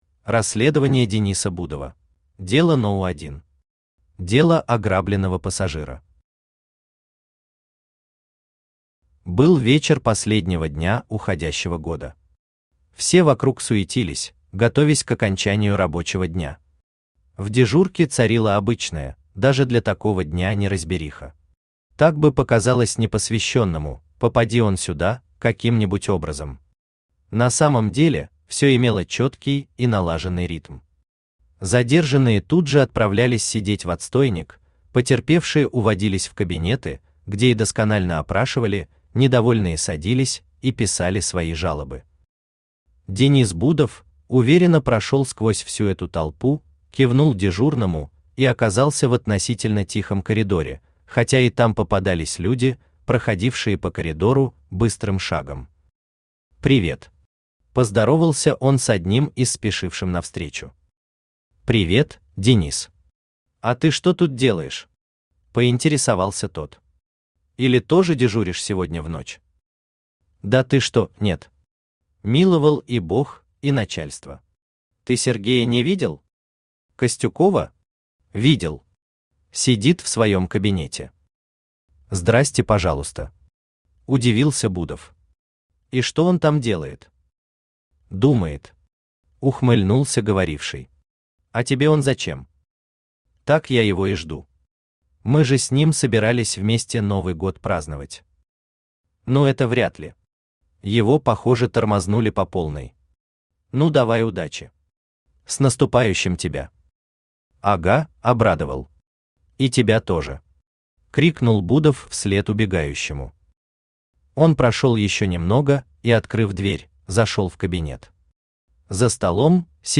Книга первая Автор Рашит Халилуллин Читает аудиокнигу Авточтец ЛитРес.